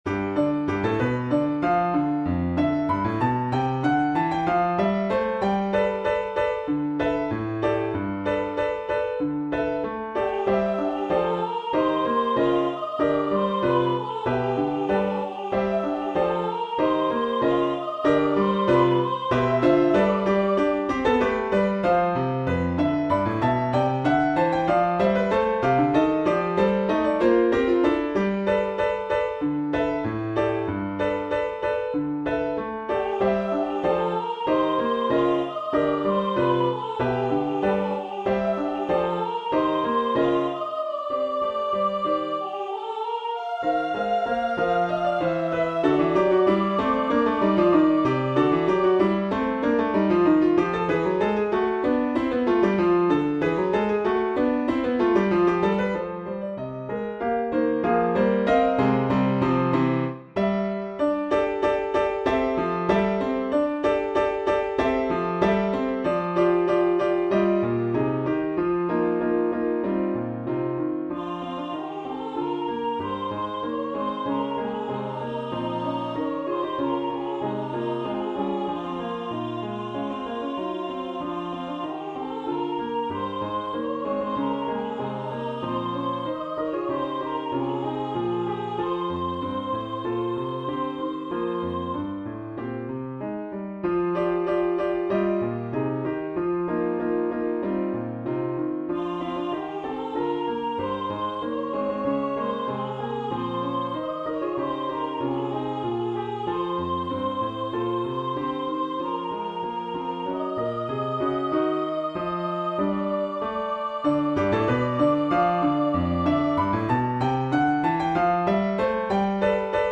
1v Voicing: Soprano solo Genre: Secular, Art song
Language: English Instruments: Keyboard